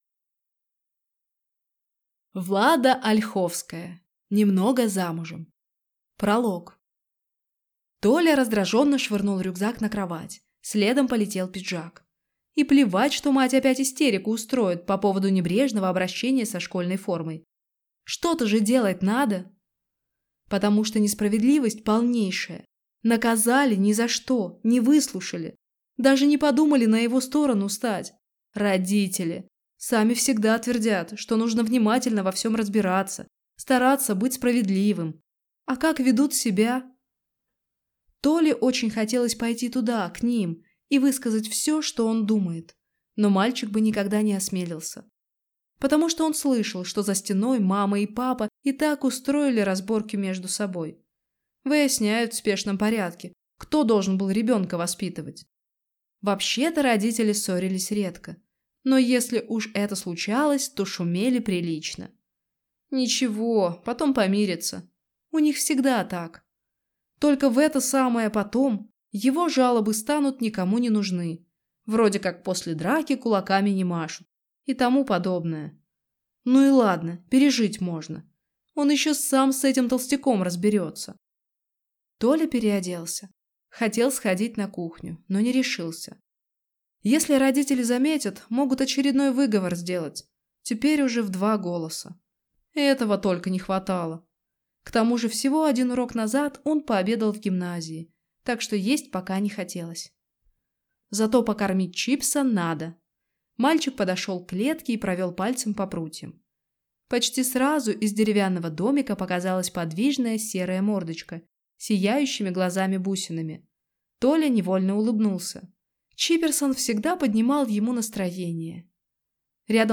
Аудиокнига Немного замужем | Библиотека аудиокниг